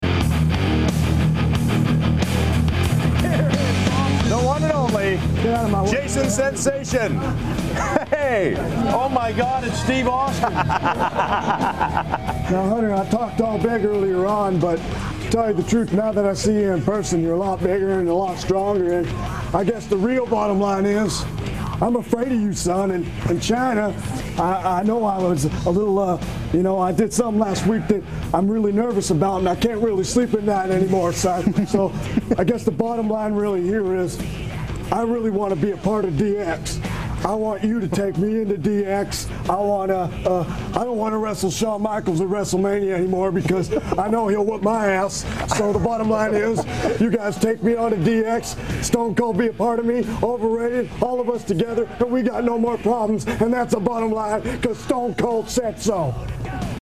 kinda sound like Austin in a Rich Little sounds like Johnny Carson level of speaking.